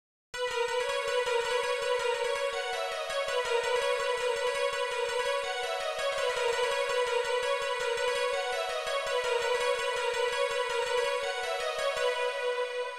Here i am using the M1 synth inside of studio one. Midi guitar 3 with loopmidi as the midi output